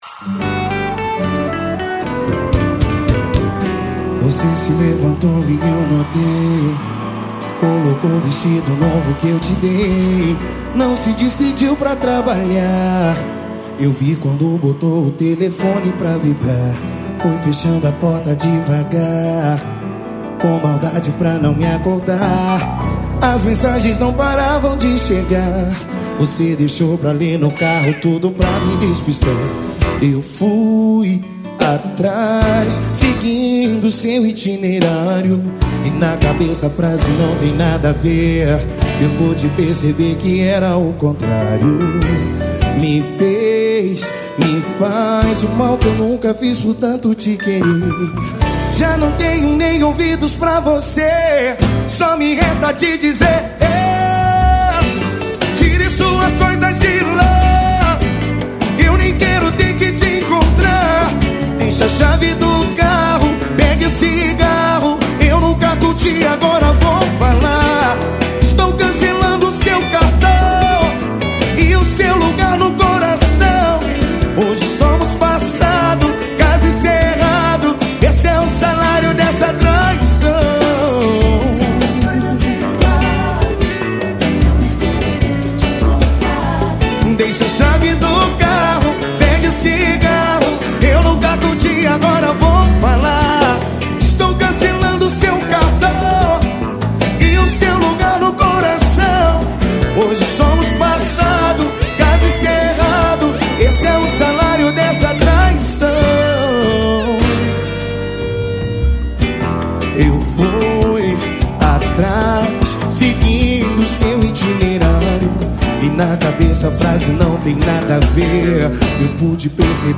Alternativo.